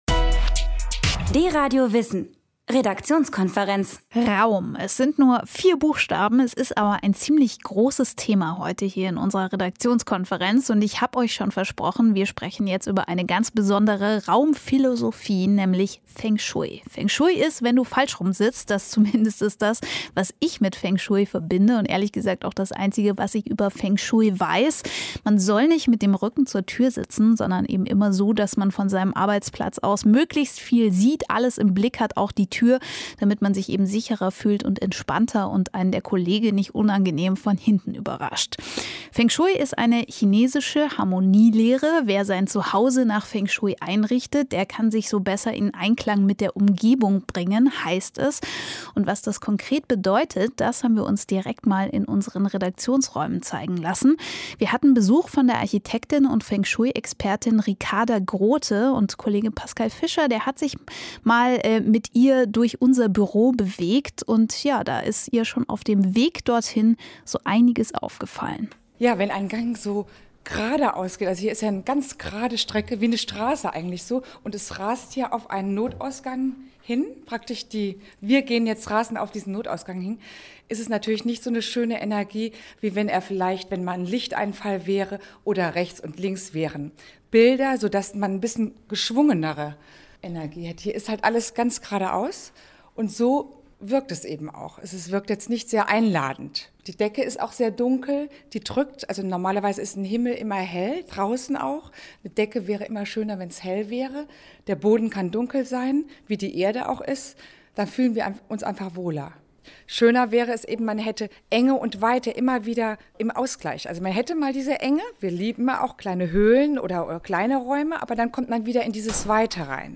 FENG SHUI INTERVIEW IN DEN RÄUMEN VON DEUTSCHLANDRADIO